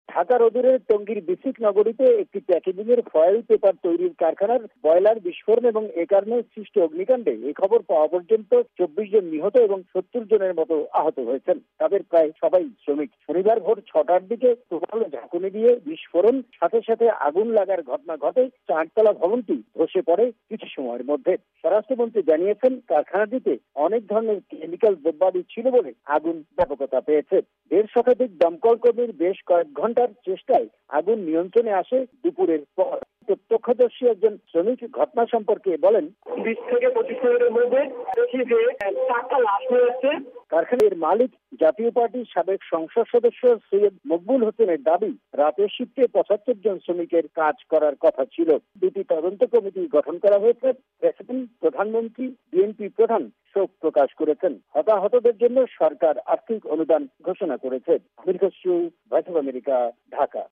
ওই কারখানাটির প্রত্যক্ষদর্শী একজন শ্রমিক ঘটনা সম্পর্কে বর্ণনা দিয়েছেন।